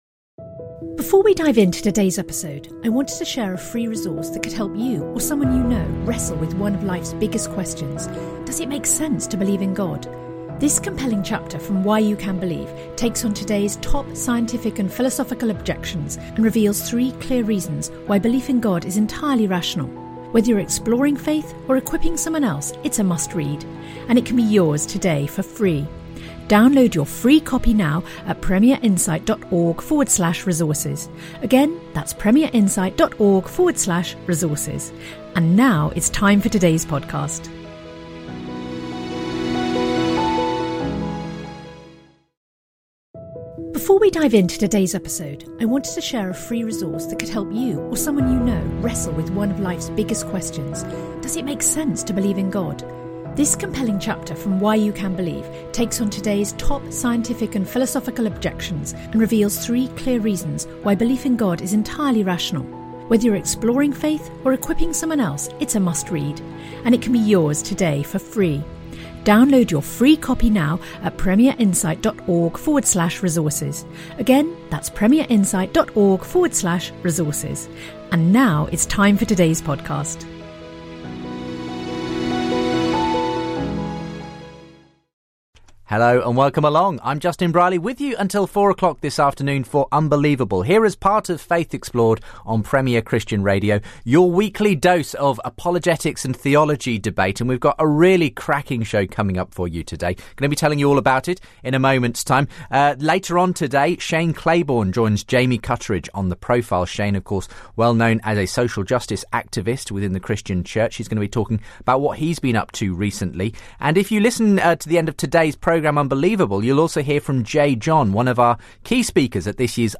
They debate who wrote Mark, whether the the Gospels came from anonymous traditions and how they received their titles.